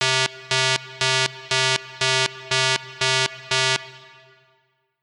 beeper.mp3